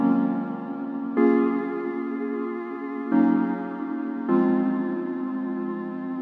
灵活的合成器效果
标签： 154 bpm Hip Hop Loops Synth Loops 1.05 MB wav Key : Unknown
声道立体声